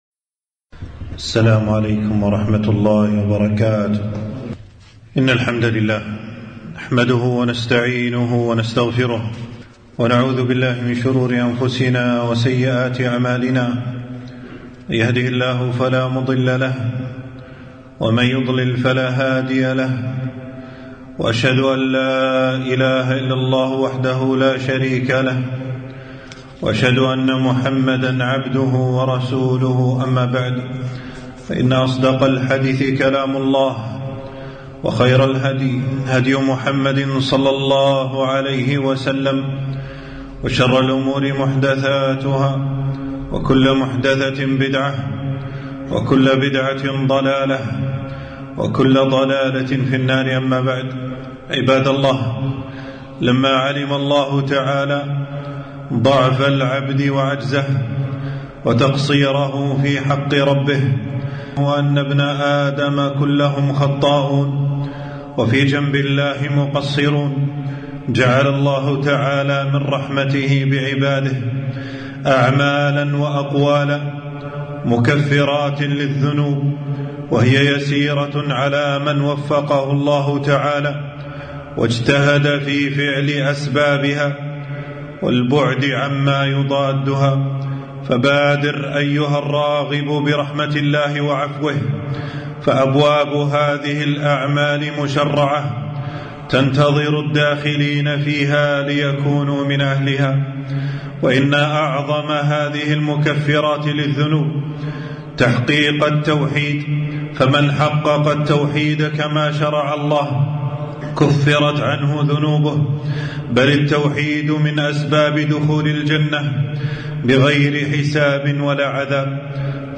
خطبة - مكفرات الخطايا والذنوب